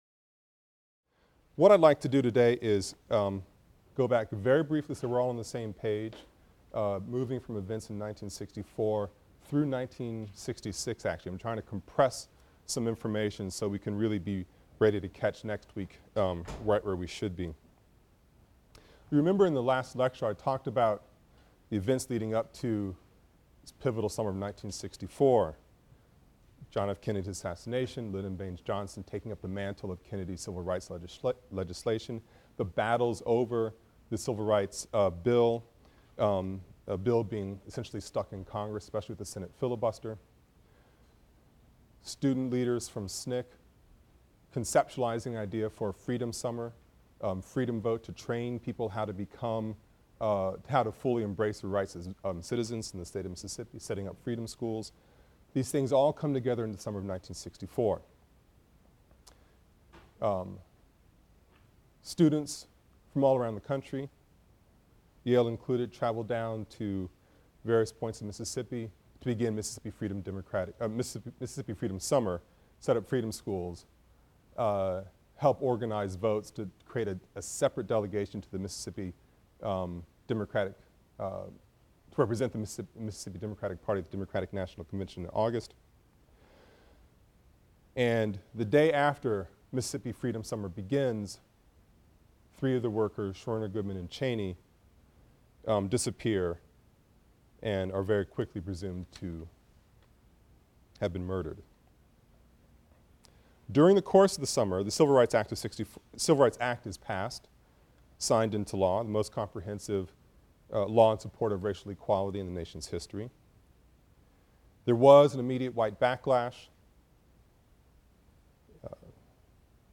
AFAM 162 - Lecture 17 - From Voting Rights to Watts (continued) | Open Yale Courses